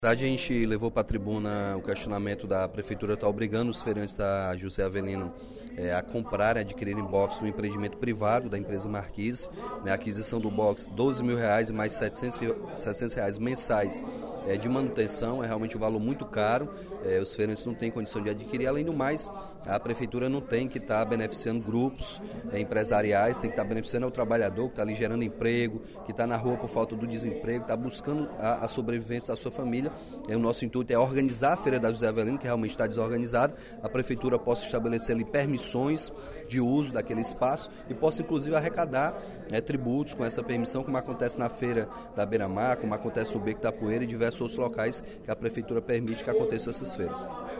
O deputado Capitão Wagner (PR) reclamou, no primeiro expediente da sessão plenária desta quinta-feira (24/09), do descaso da Prefeitura de Fortaleza com feirantes do ramo de confecção.